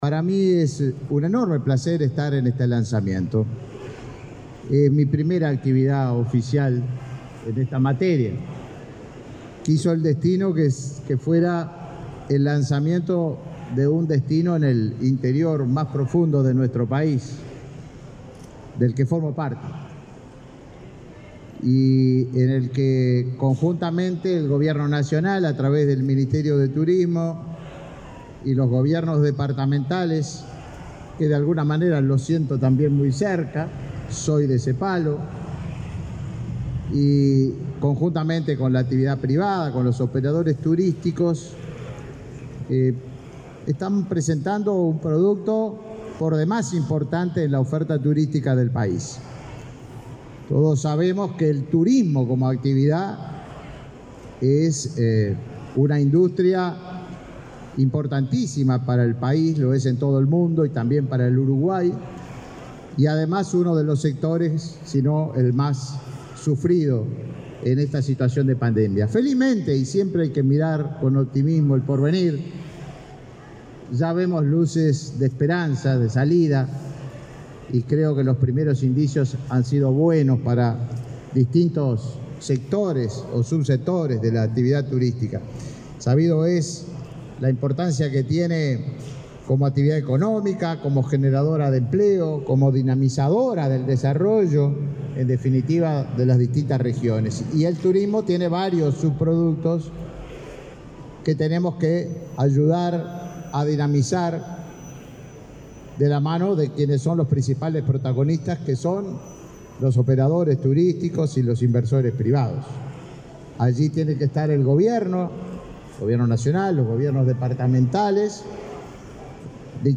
Palabras del ministro de Turismo, Tabaré Viera
Palabras del ministro de Turismo, Tabaré Viera 27/08/2021 Compartir Facebook X Copiar enlace WhatsApp LinkedIn El Ministerio del Turismo presentó, este viernes 27, el Destino Termas para la temporada primavera-verano 2021. El acto fue presidido por el ministro Tabaré Viera.